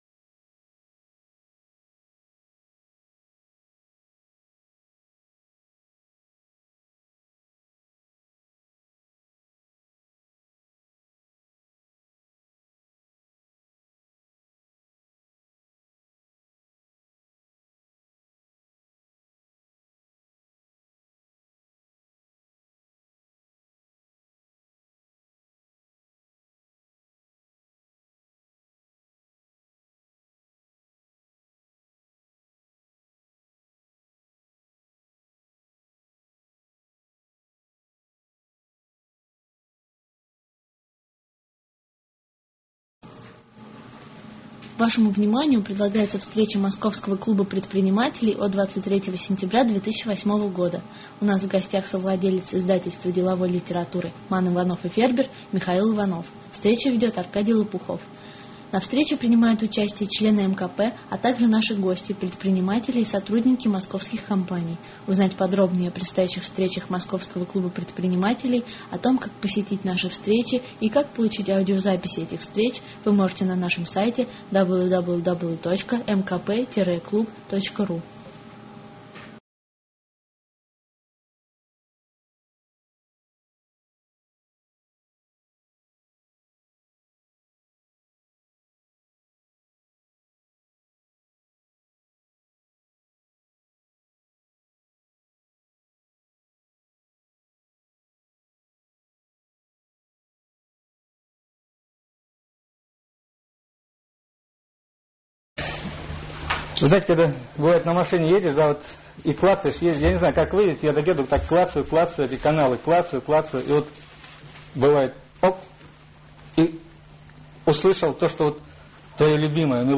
Мероприятие ОТКРЫТАЯ встреча МКП